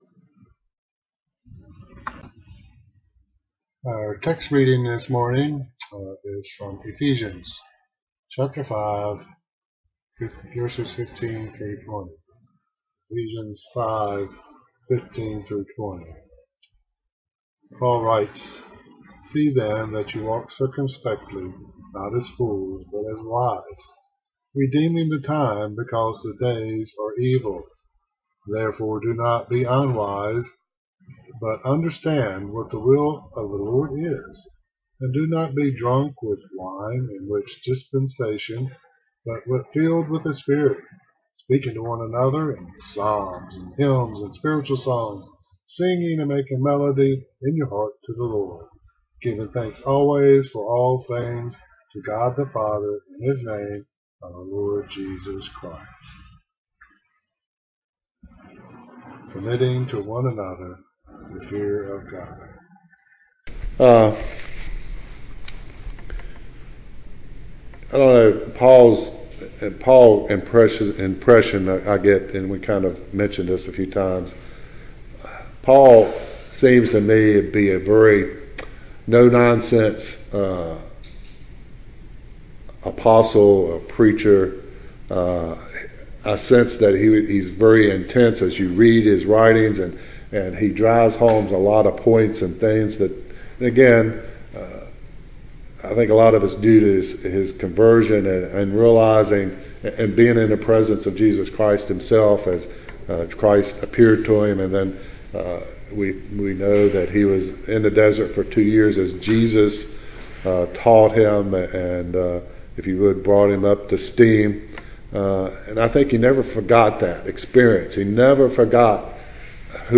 Note: First part has poor audio but clears up.